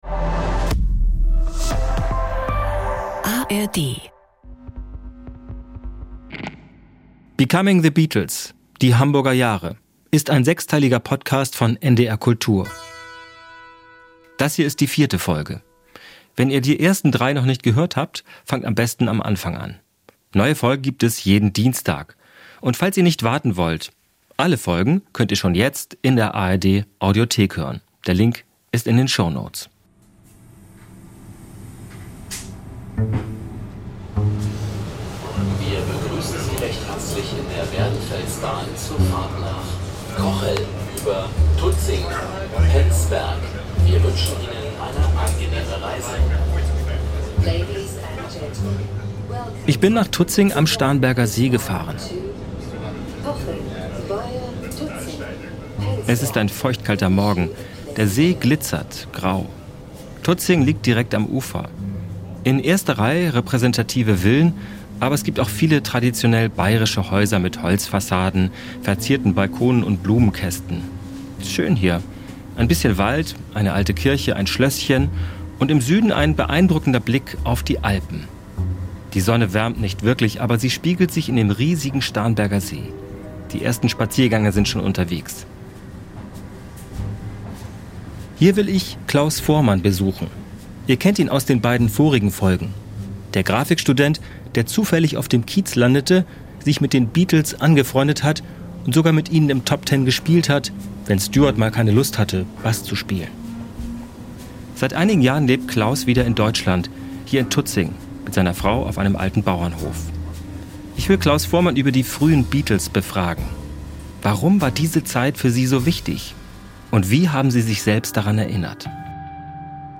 Ein Podcast mit viel Musik, O-Tönen von Zeitzeugen und seltenem Archivmaterial.